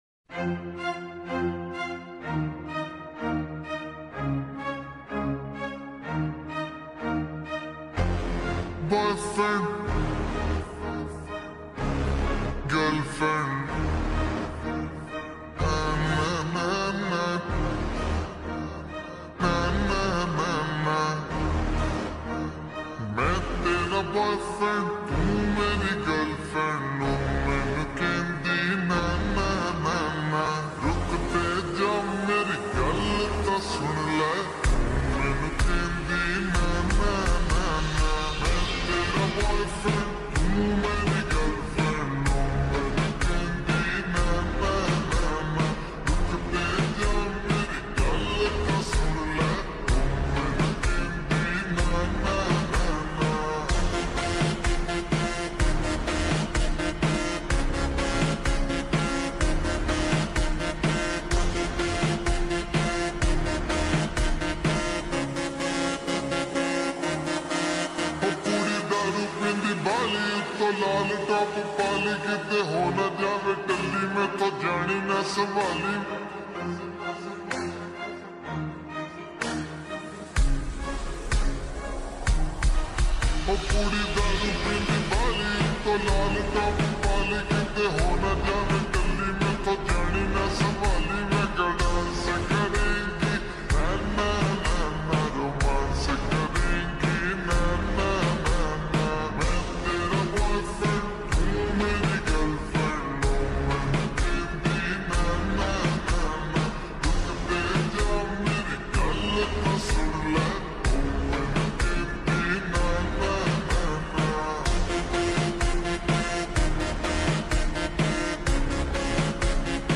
SLOWED REVERB SONG